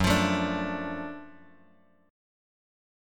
GbmM7#5 chord